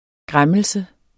Udtale [ ˈgʁaməlsə ]